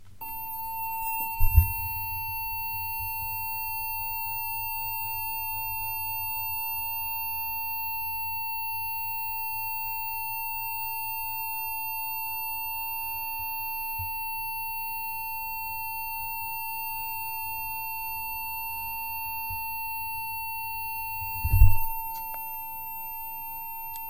modulating tone to speak thinking